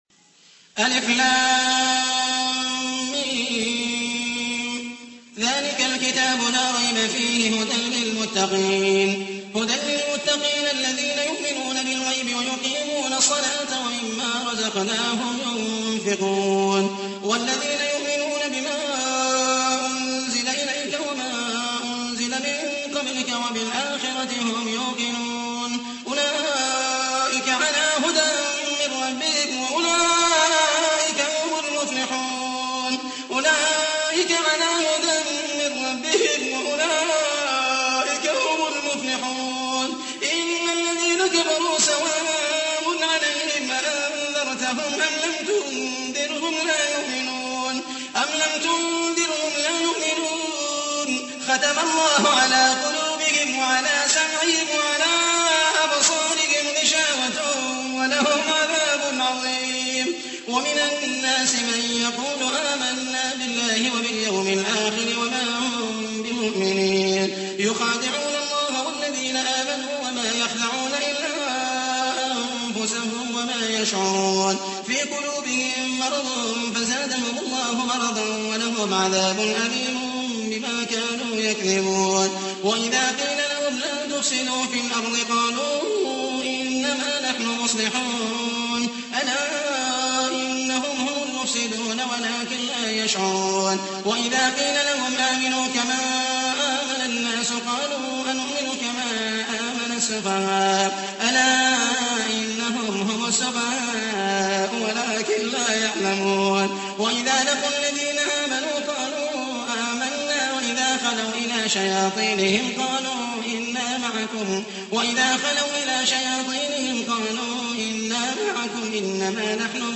تحميل : 2. سورة البقرة / القارئ محمد المحيسني / القرآن الكريم / موقع يا حسين